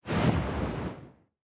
fissure-explosion-4.ogg